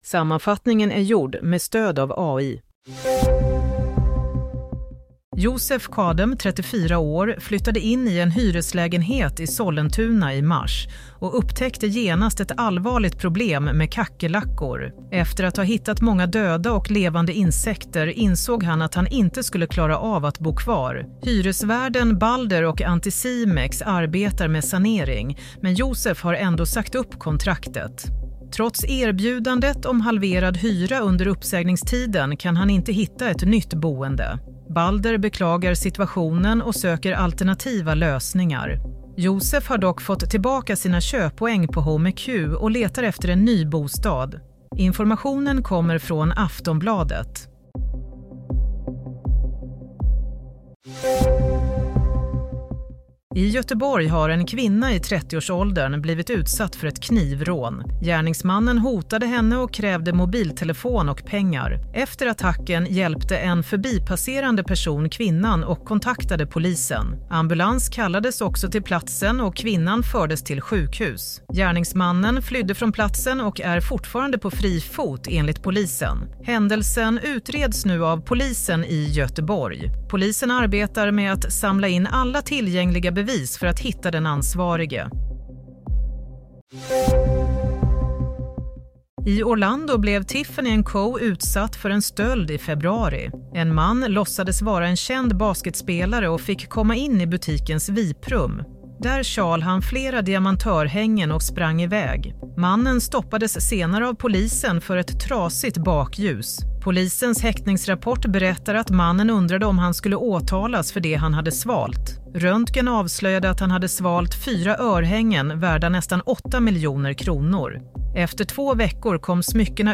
Nyhetssammanfattning - 22 mars 22:00